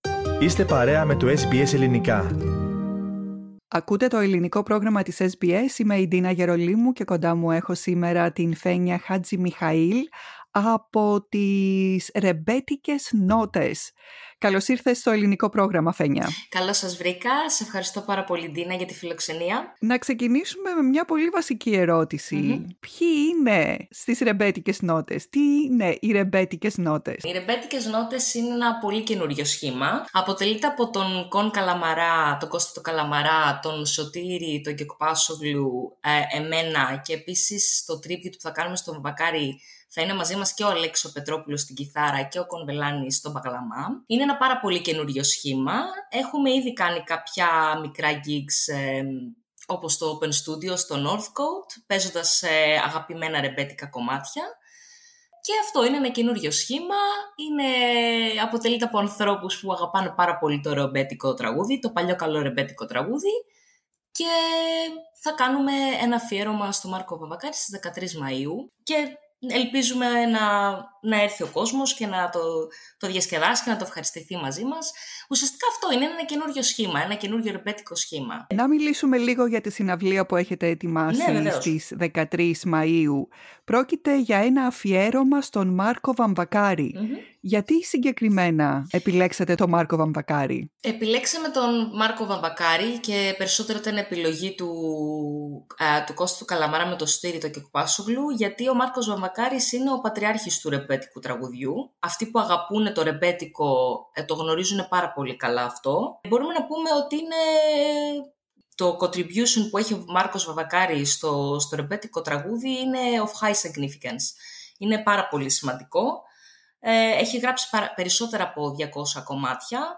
SBS Greek